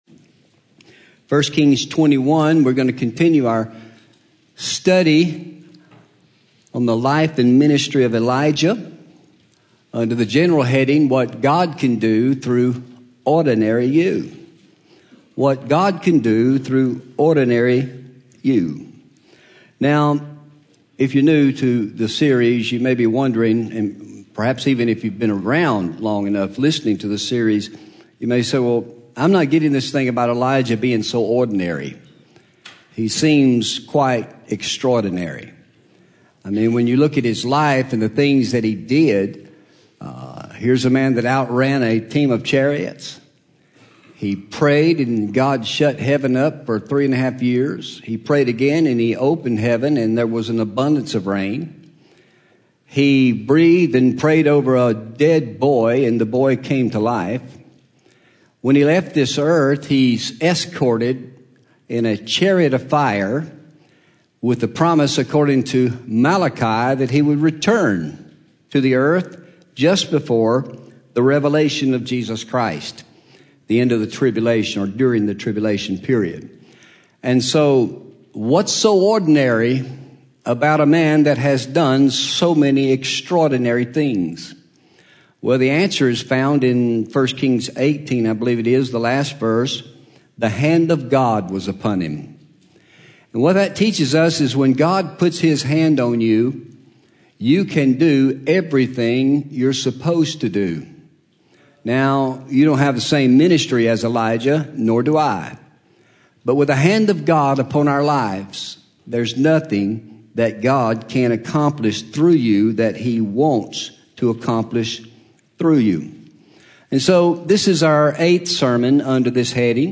Home › Sermons › The Ahab Family